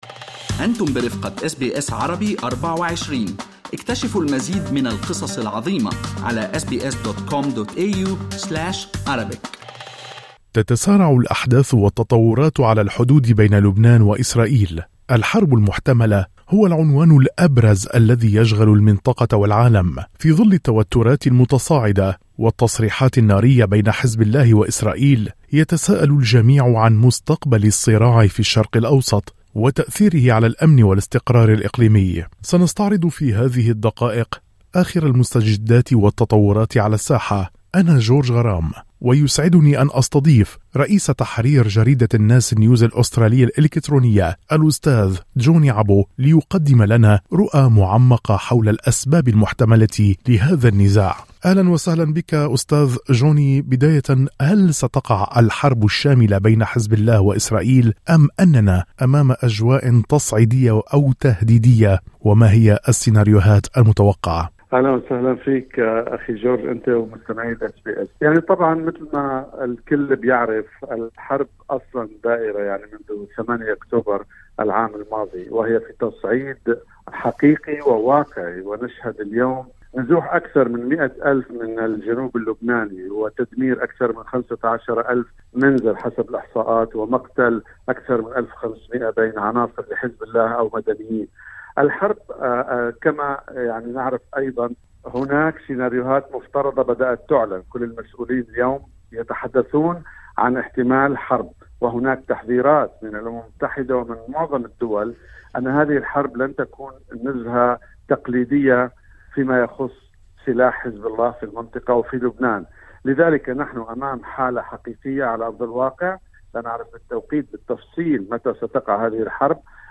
في حوار مع أس بي أس عربي24